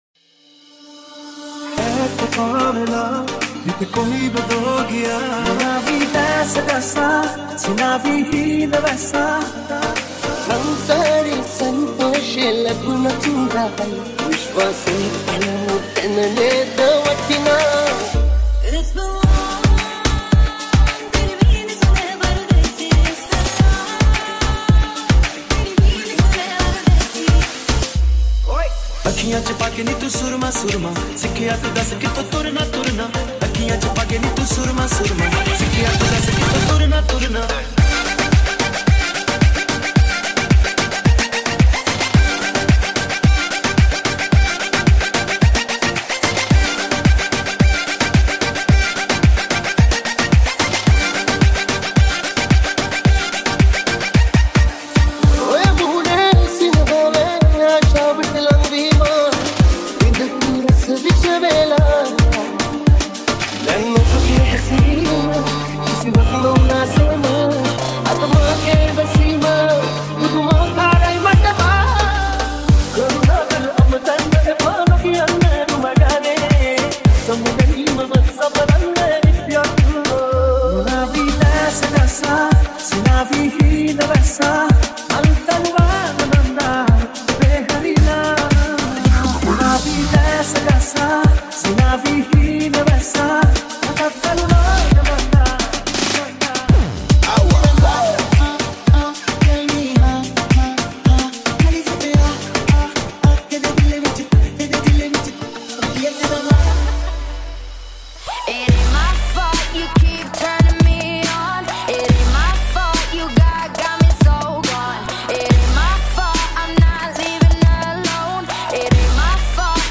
Sinhala Mashup | Party Dance Mashup | Dance Dj Remix